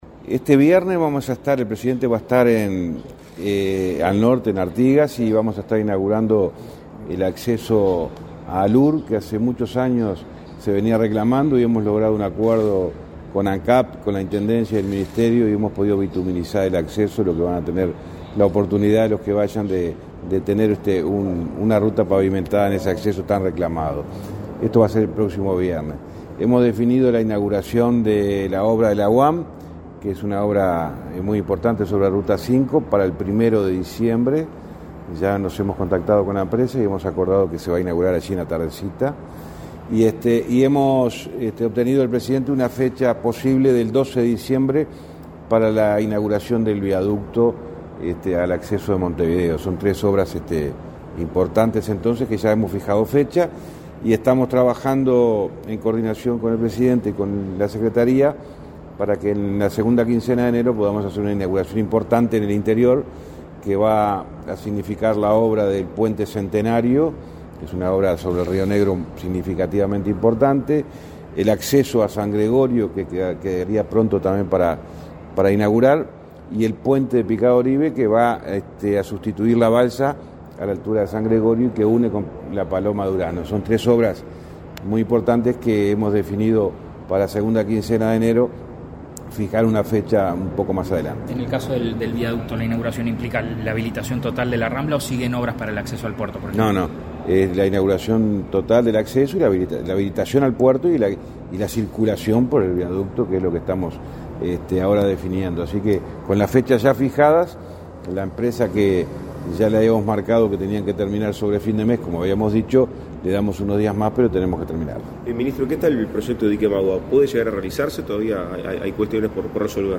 Declaraciones a la prensa del ministro del MTOP, José Luis Falero